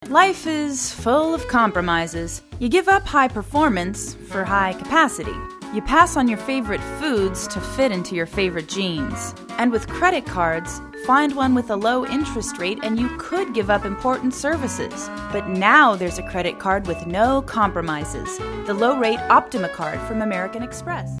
Sprecherin englisch.
Sprechprobe: Sonstiges (Muttersprache):